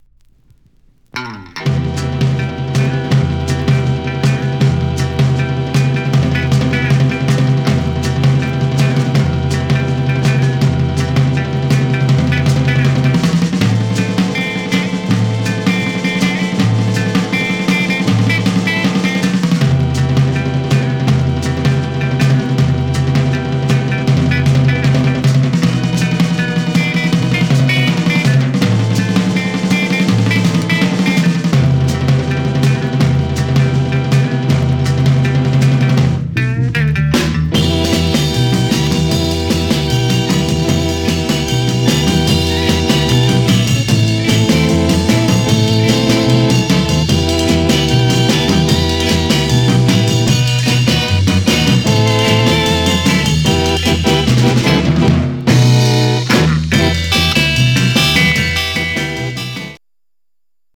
Stereo/mono Mono
R & R Instrumental